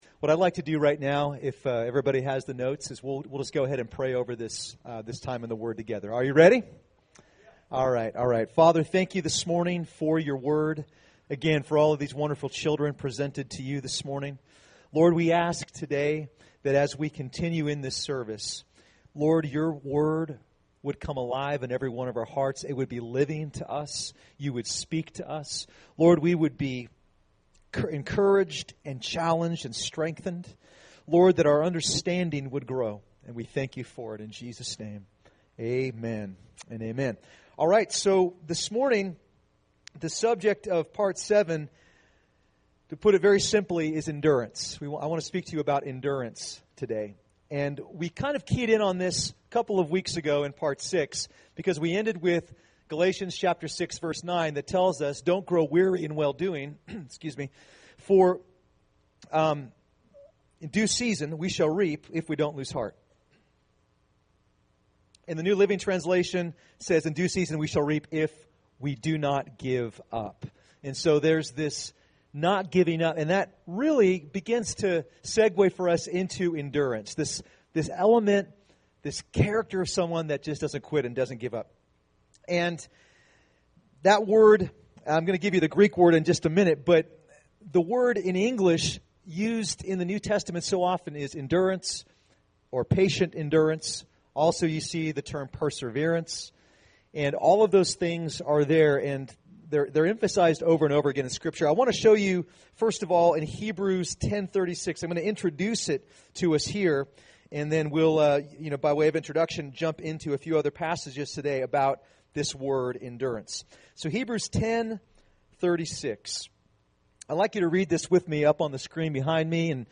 Recorded at New Life Christian Center, Sunday, May 15, 2016 at 11 AM.